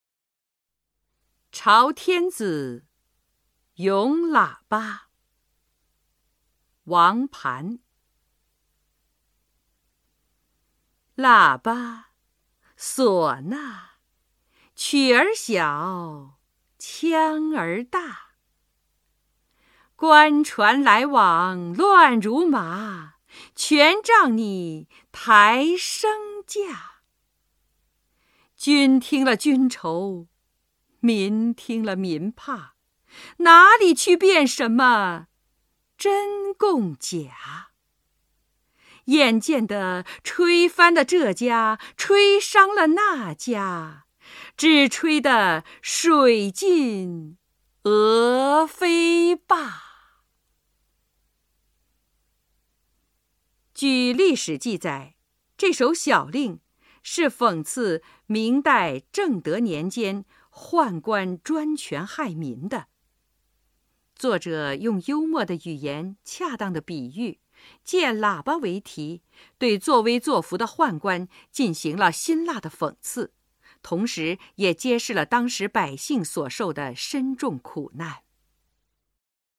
首页 视听 语文教材文言诗文翻译与朗诵 初中语文八年级下册
王磐《朝天子·咏喇叭》（喇叭）原文与译文（含赏析、朗读）